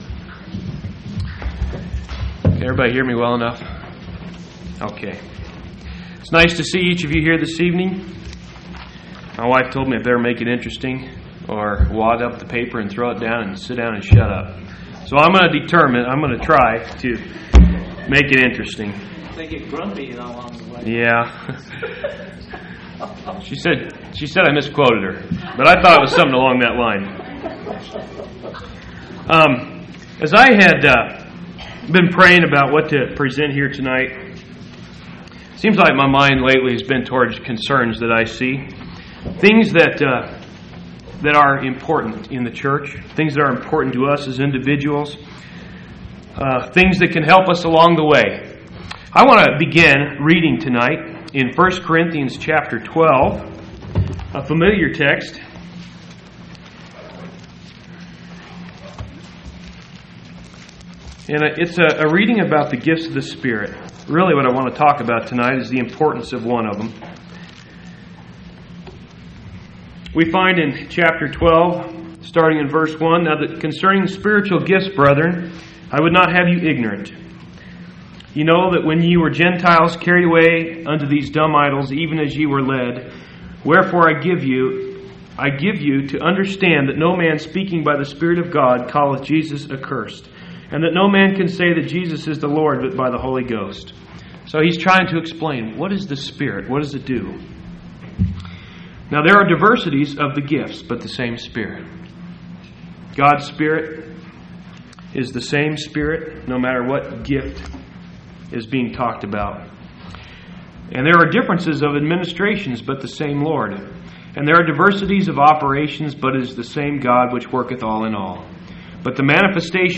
7/19/1998 Location: Phoenix Local Event